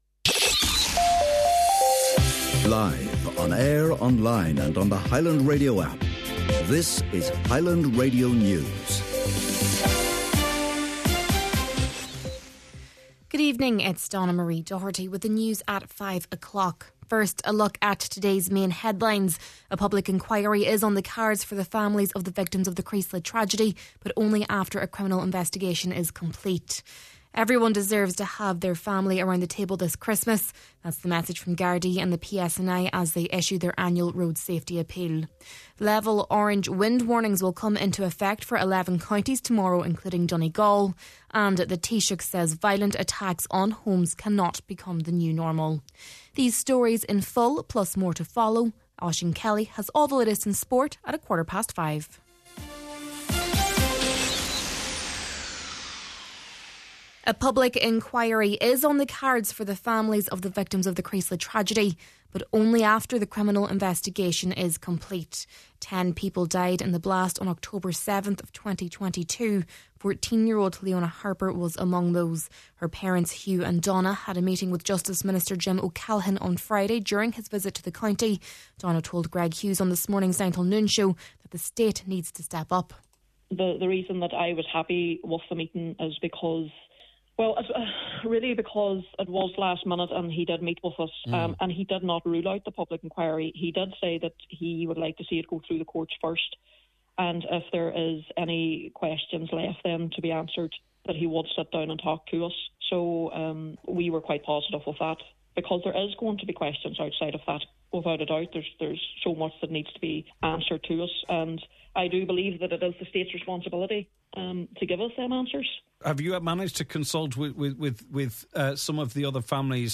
Main Evening News, Sport and Obituary Notices – Monday, December 8th